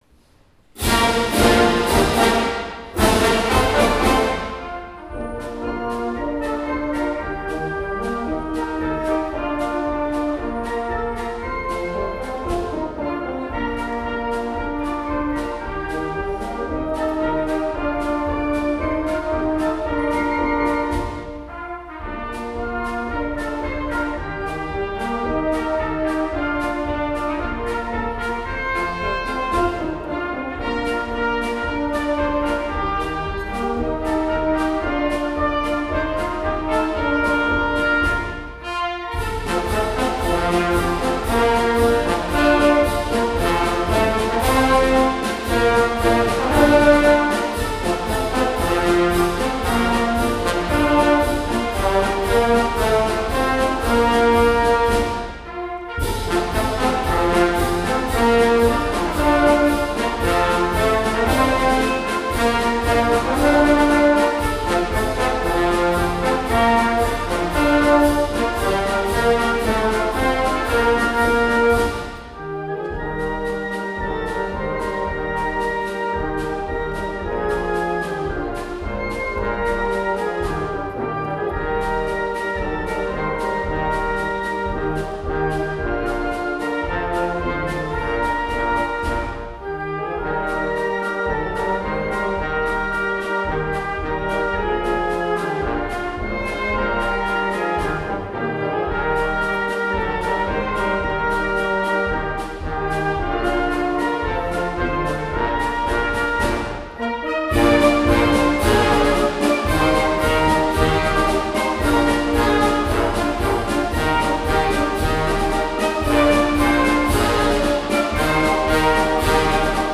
演奏：明石高校OB吹奏楽団
明石市民会館大ホール